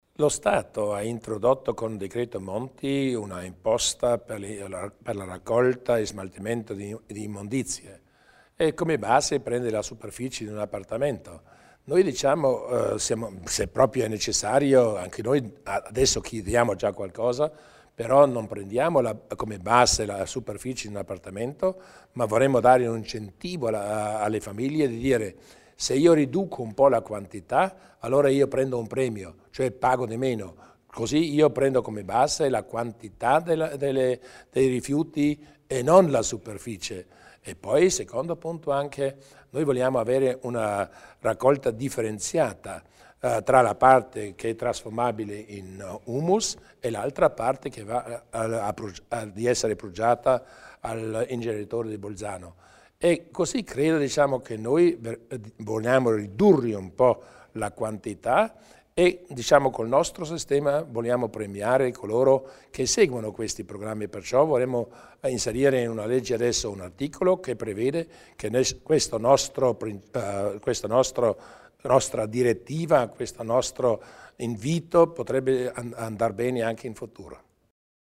Il Presidente Durnwalder illustra i progetti in tema di tassa sui rifiuti